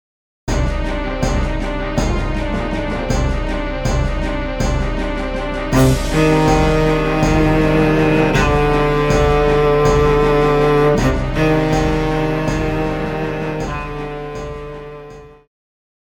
Cello
Band
Instrumental
World Music,Electronic Music
Only backing